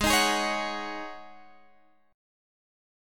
Listen to Ab7sus4 strummed